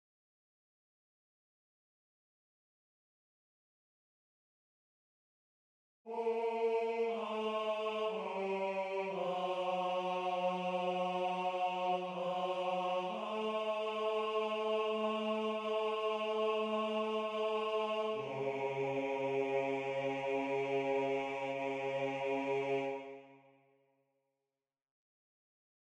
Key written in: C# Major
Type: Barbershop